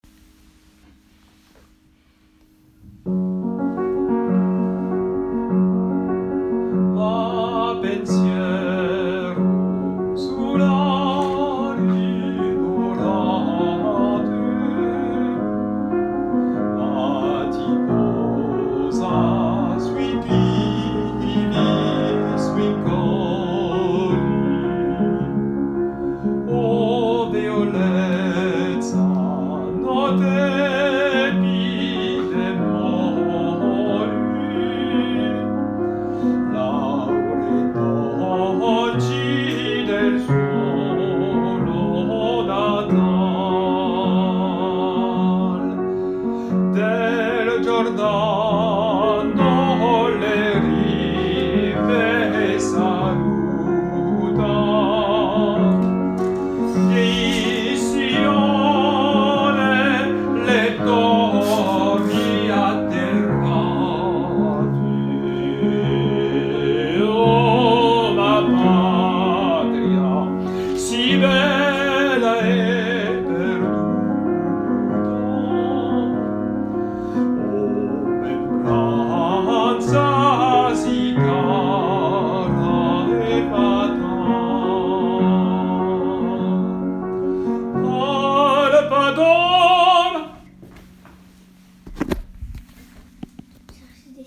Partition pour choeur seul fichier PDF
Tutti